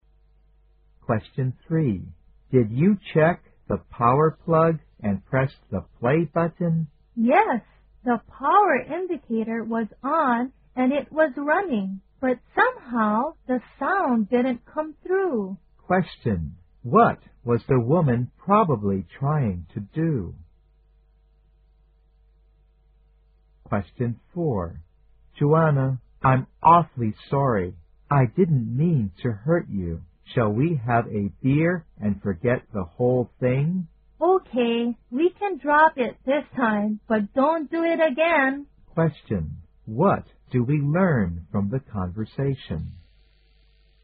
在线英语听力室115的听力文件下载,英语四级听力-短对话-在线英语听力室